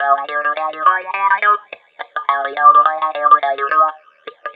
RI GTR 1.wav